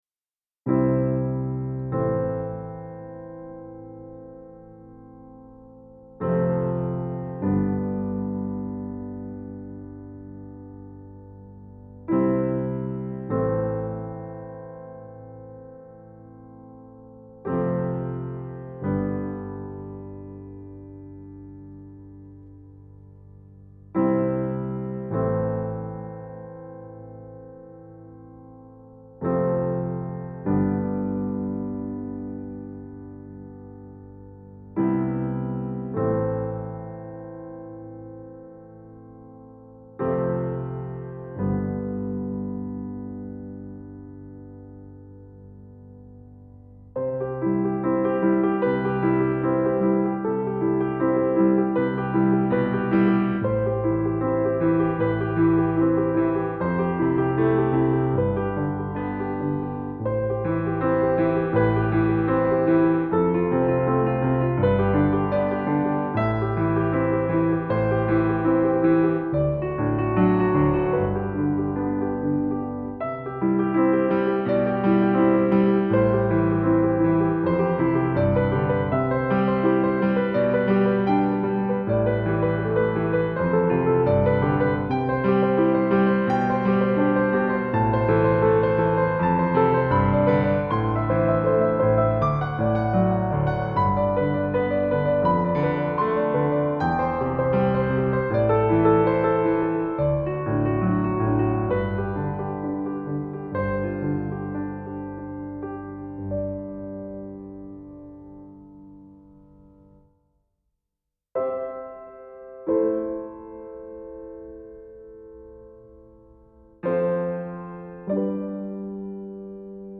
موسیقی بیکلام